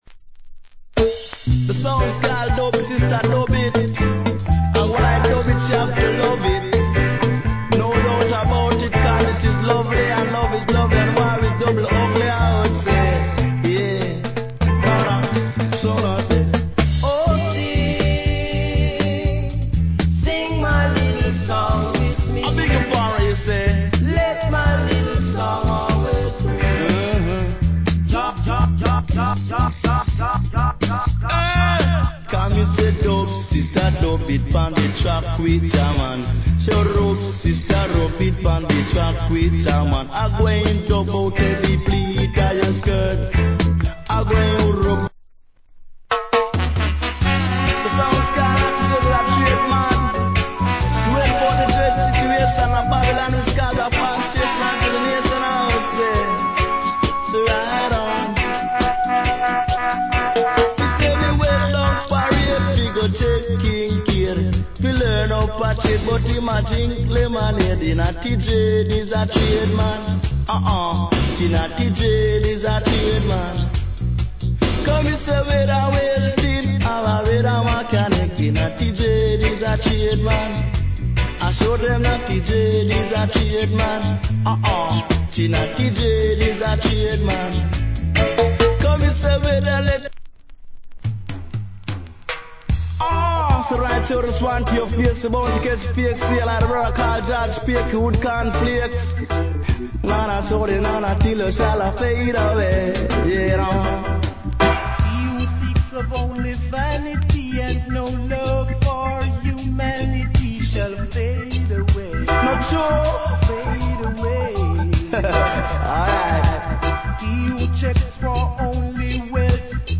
REGGAE
Good DJ Style Compilation Album.
70's